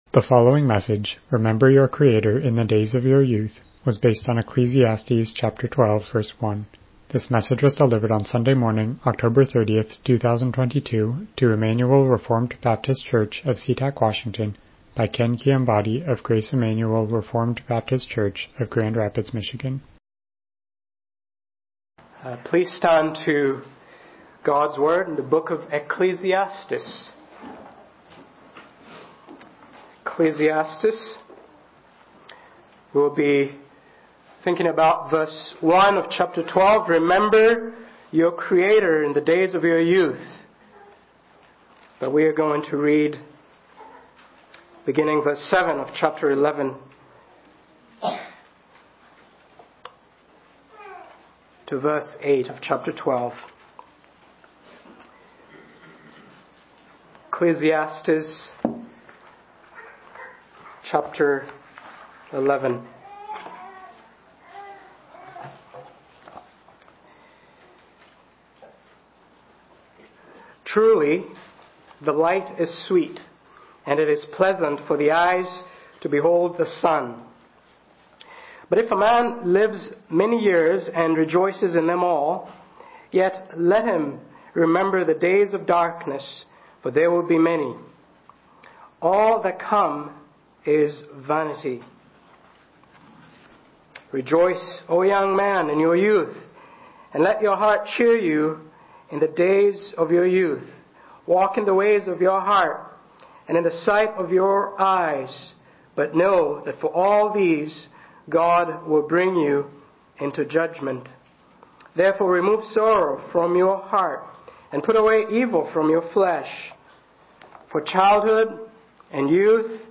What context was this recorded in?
Passage: Ecclesiastes 12:1 Service Type: Morning Worship « LBCF Chapter 18